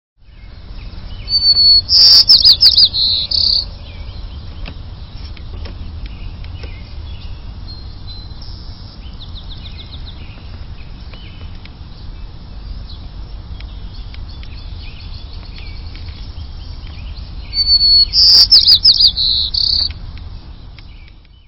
White-crowned Sparrow
Bird Sound
Song a series of clear whistles followed by buzzes or trills on different pitches.
White-crownedSparrow.mp3